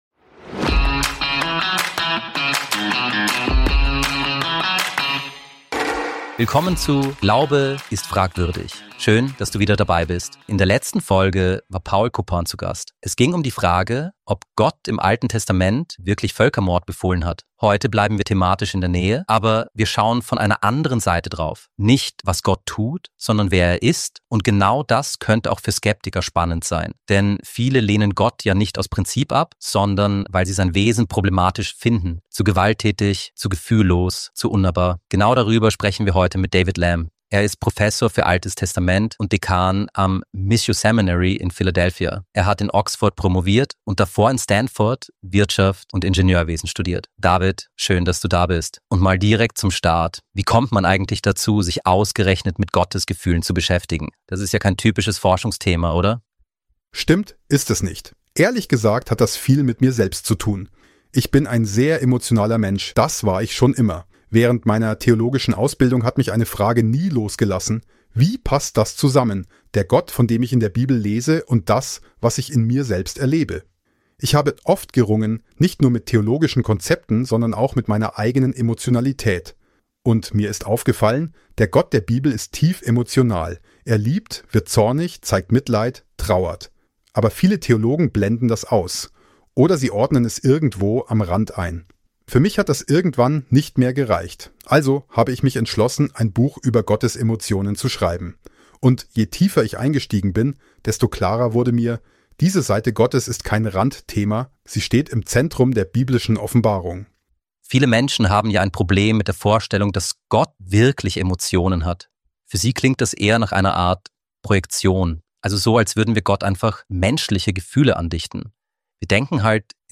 Eine Übersetzung des Gesprächs